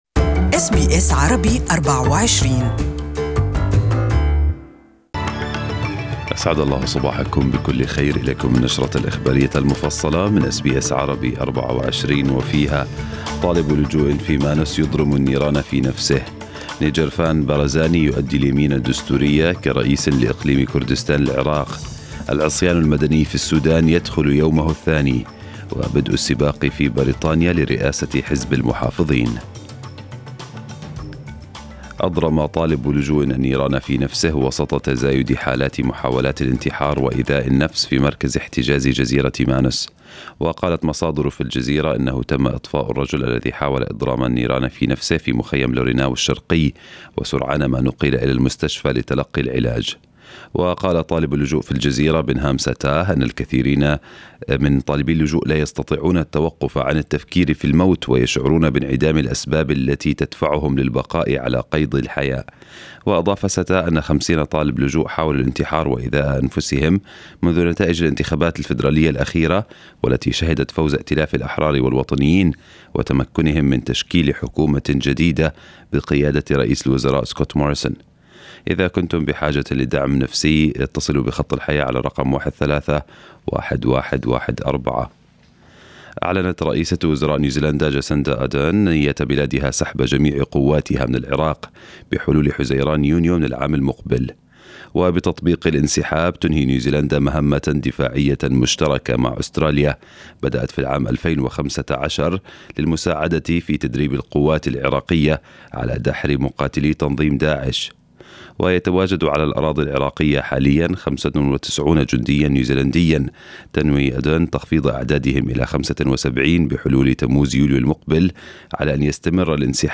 A24 Morning N ews Bulletin Source: Getty